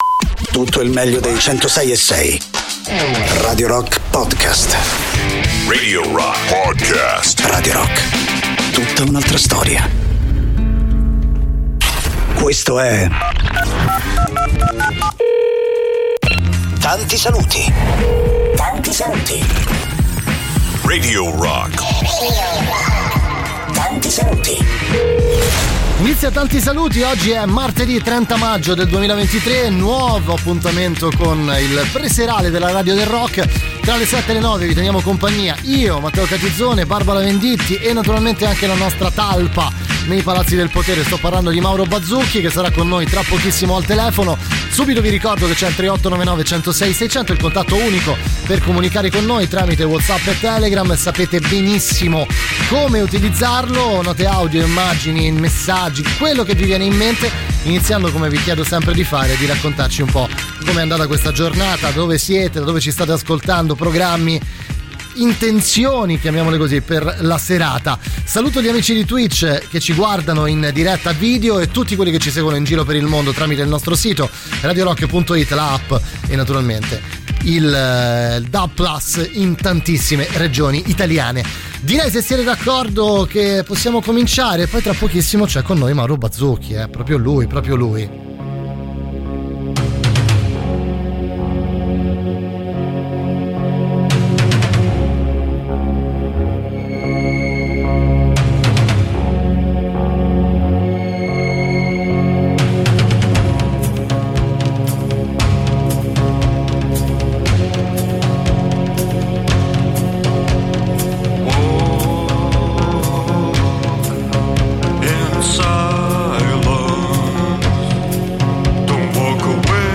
in diretta dal lunedì al venerdì, dalle 19 alle 21